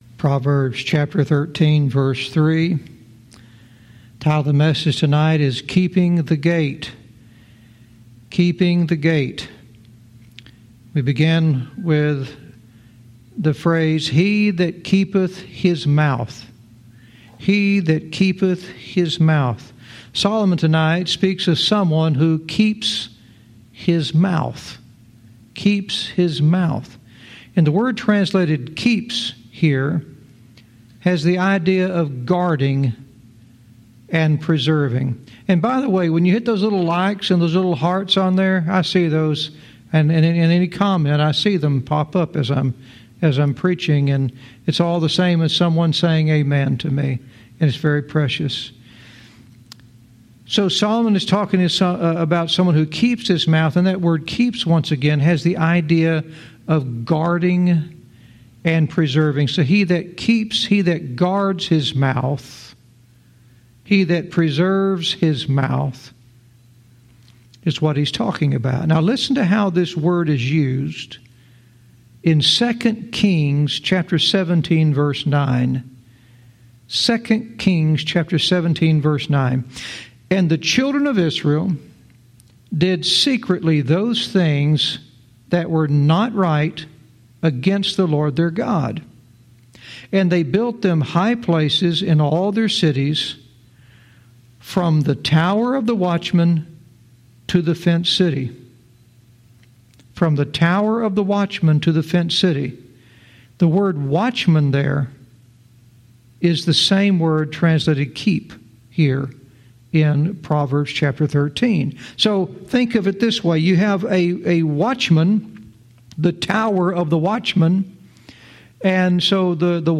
Verse by verse teaching - Proverbs 13:3 "Keeping the Gate"